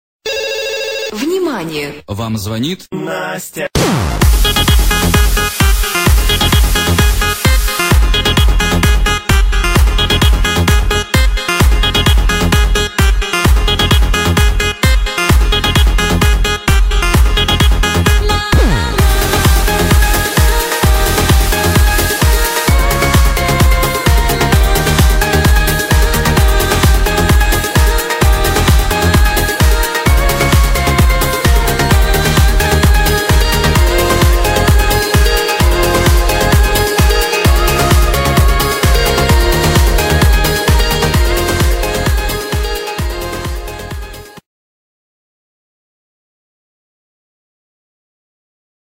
Русские рингтоны